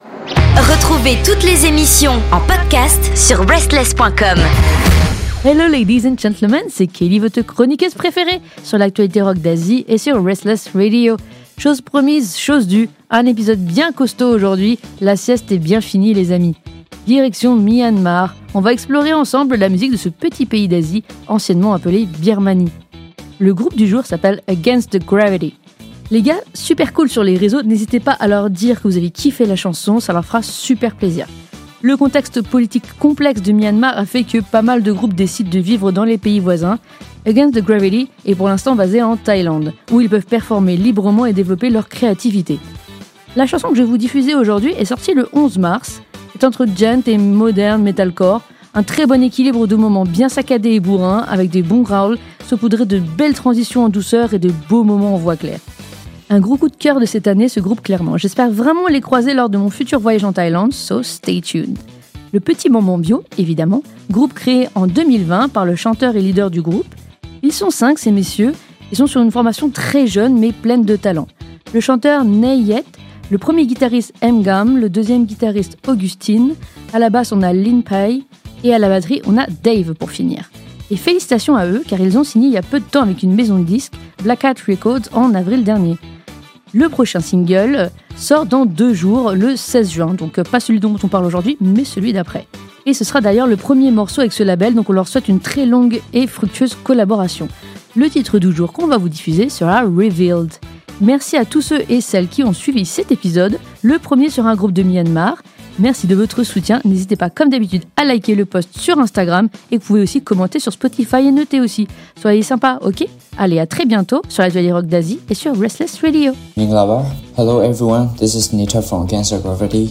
AGAINST THE GRAVITY nous envoie du lourd cette semaine. Direction Myanmar pour le son de la semaine avec cette jeune formation mélangeant le Djent et le Metal.